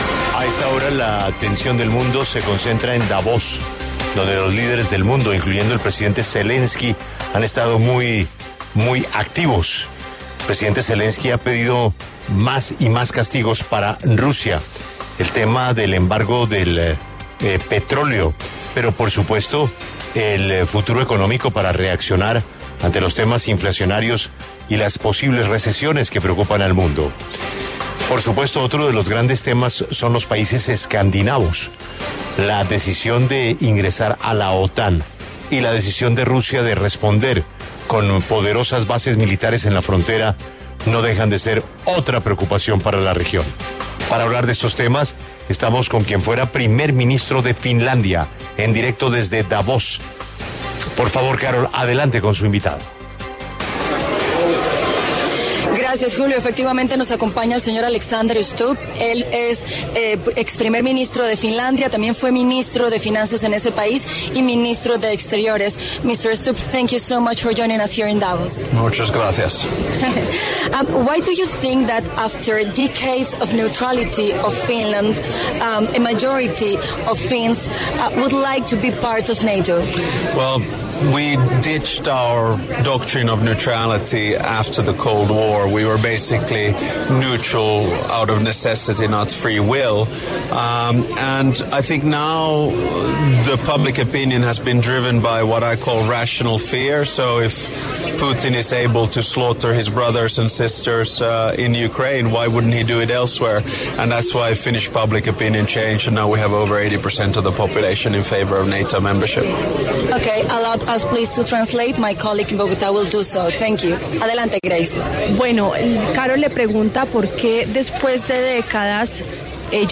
Alexander Stubb, ex primer ministro de Finlandia, habló en La W a propósito de la petición de adhesión de su país a la OTAN.
En el encabezado escuche la entrevista completa con Alexander Stubb, ex primer ministro de Finlandia.